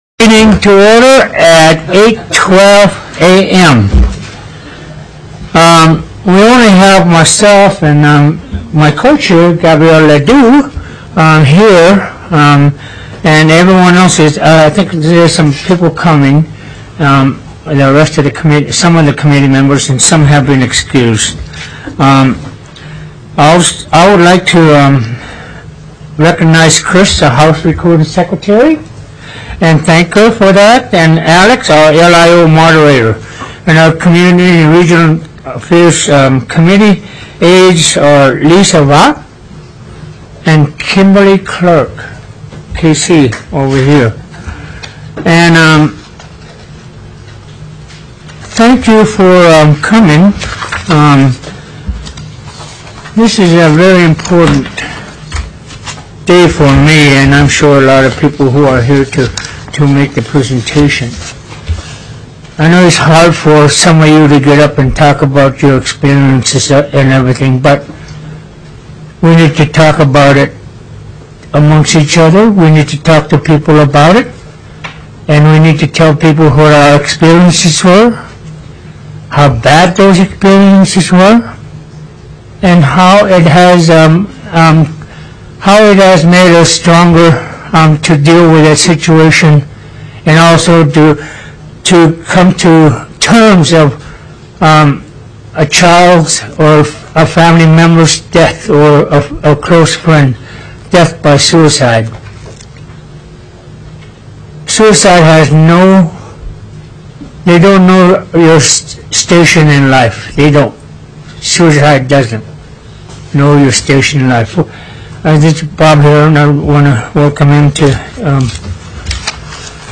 TELECONFERENCED